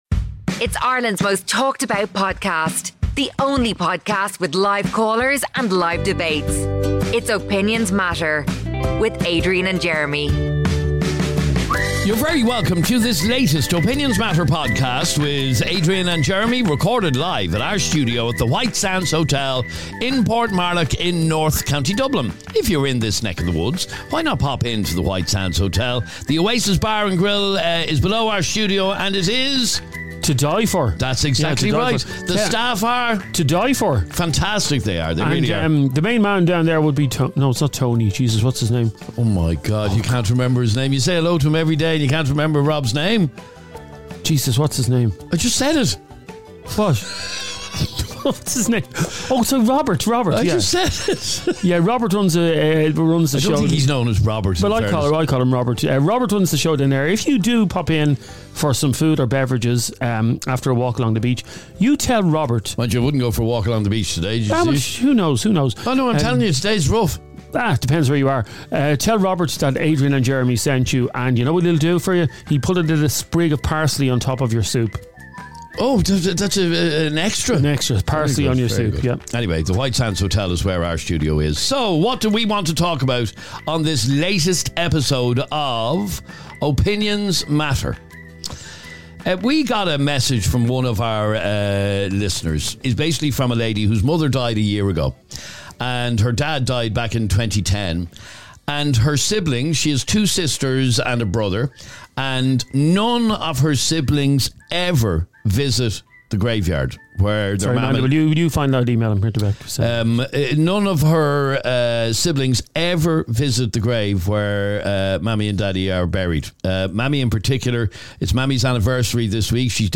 Live On Air
during a conversation about dogs being off leads
The heated exchange